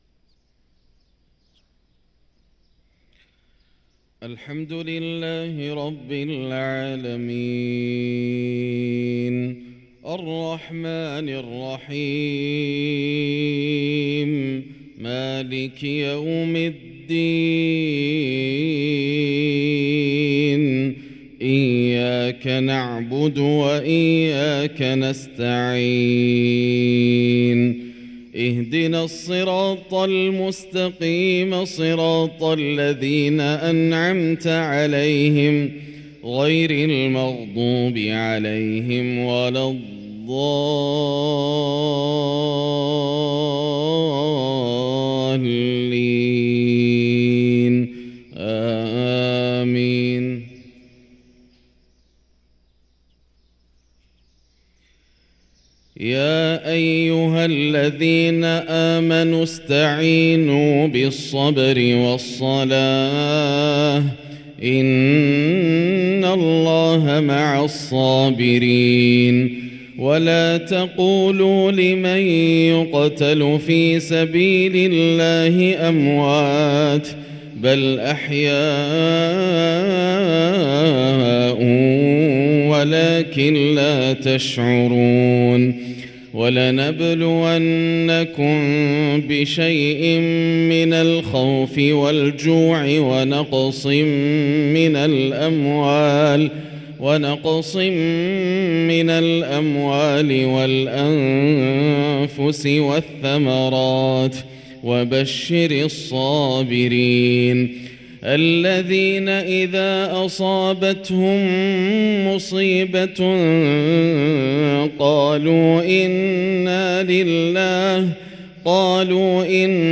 صلاة الفجر للقارئ ياسر الدوسري 7 شعبان 1444 هـ